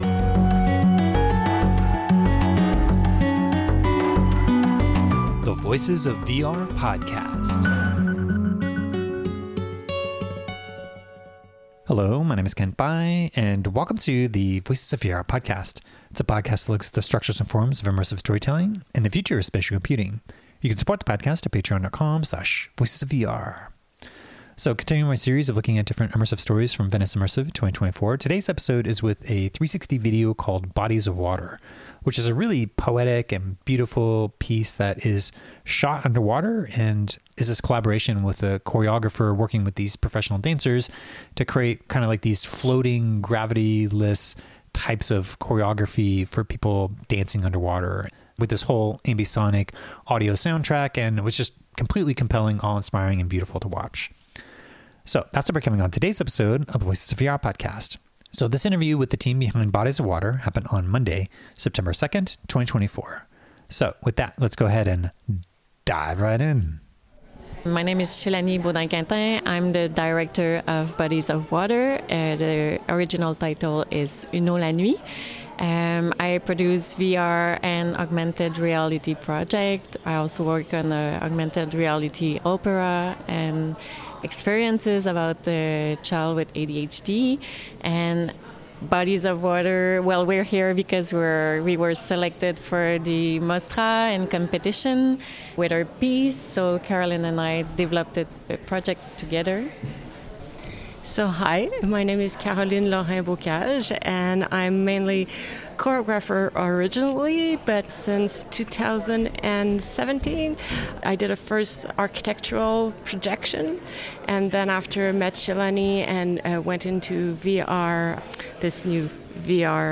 at Venice Immersive 2024